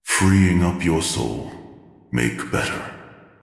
This voice set comes with reverberation echo effect, and the voice content is related to the attack type mind control.